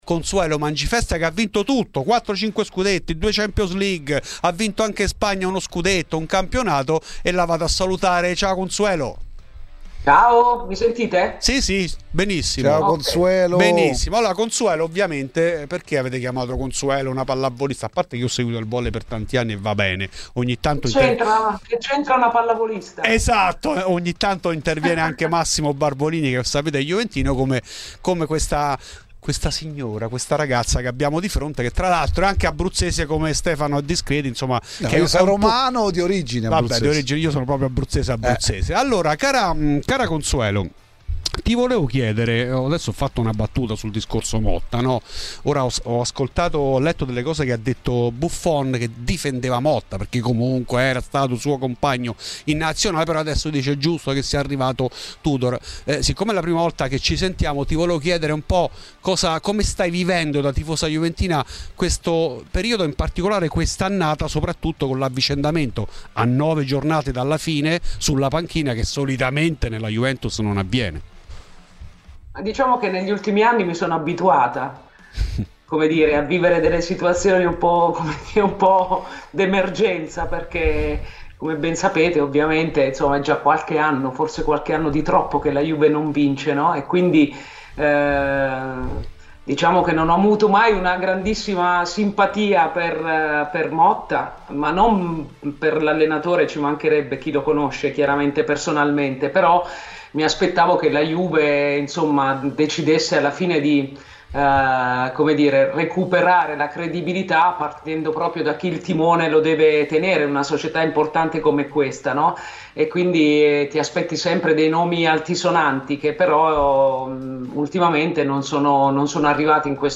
Nel podcast puoi ascoltare l'intervista integrale